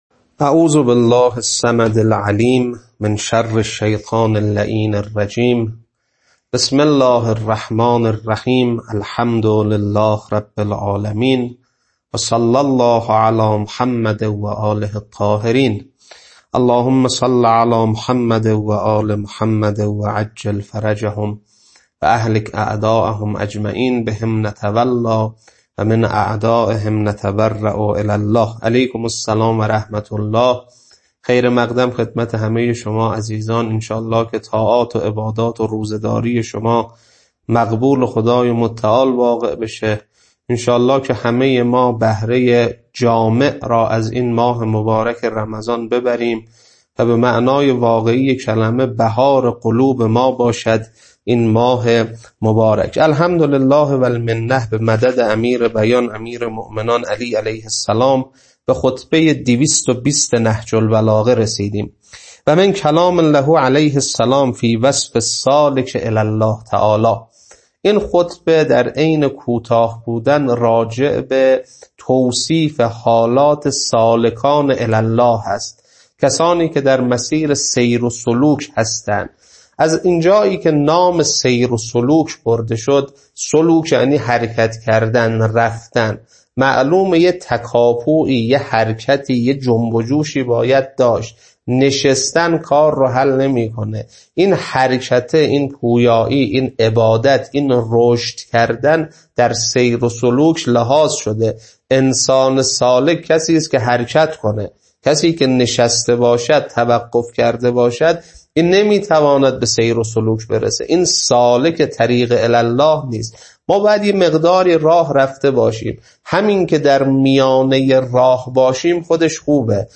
خطبه-220.mp3